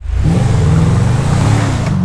Index of /server/sound/vehicles/tdmcars/gtav/mesa3